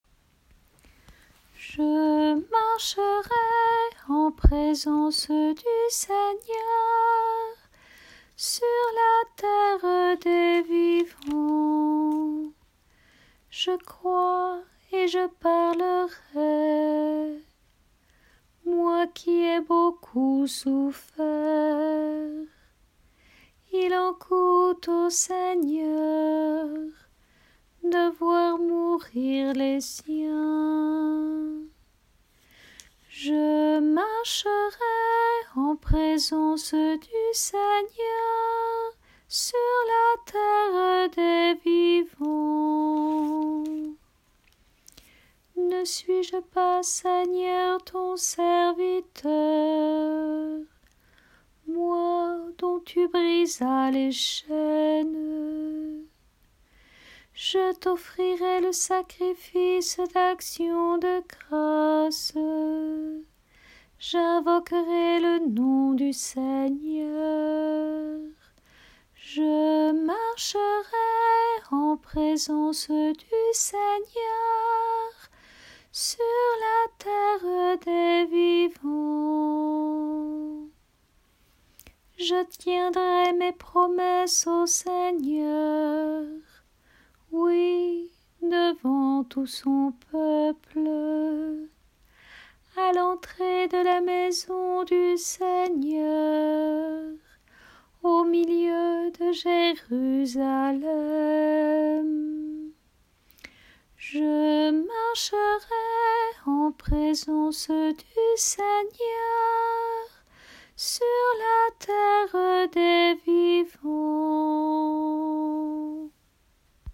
Chorale psaumes année B – Paroisse Aucamville Saint-Loup-Cammas